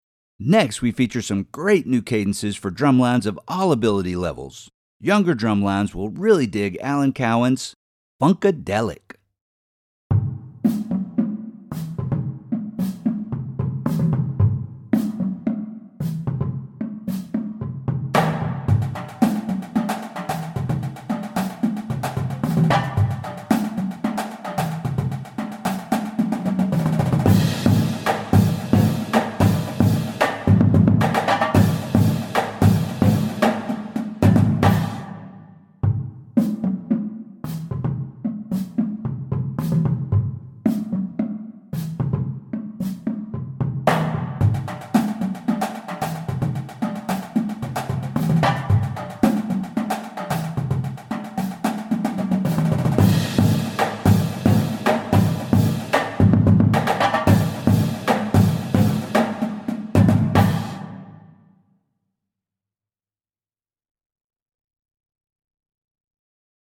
Voicing: Cadence